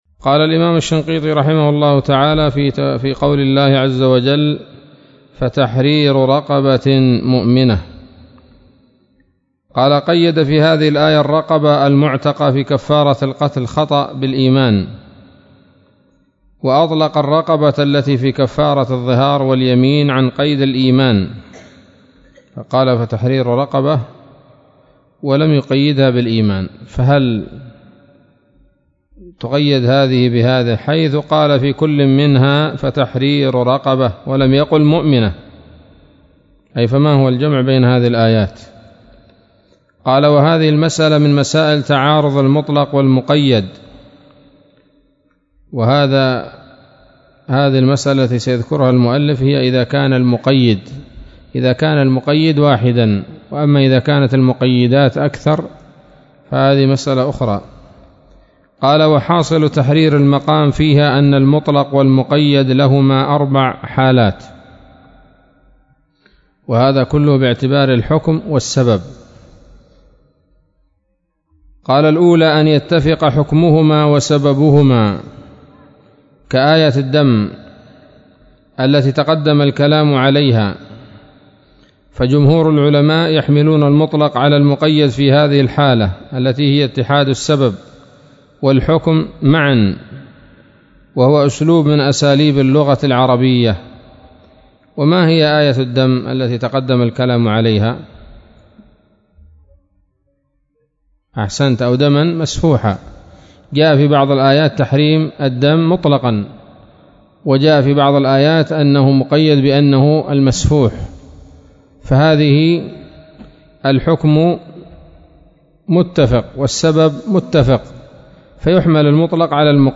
الدرس الحادي والثلاثون من دفع إيهام الاضطراب عن آيات الكتاب